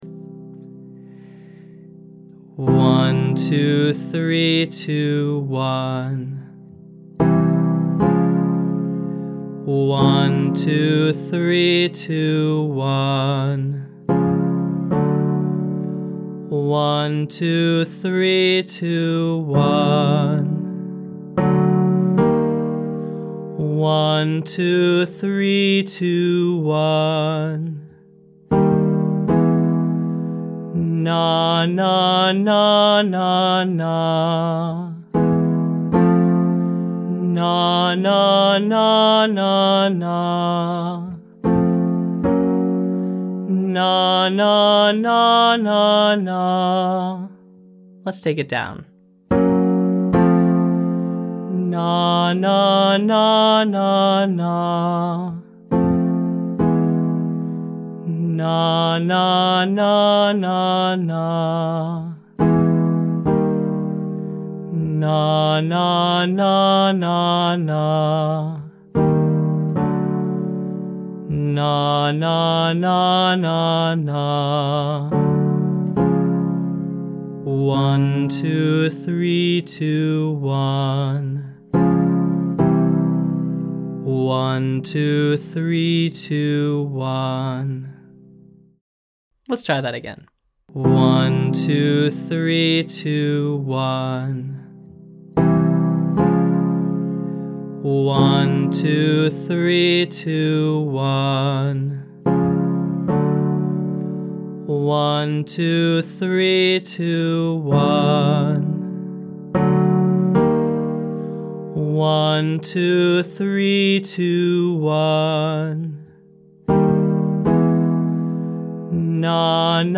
Matching Another Voice - Online Singing Lesson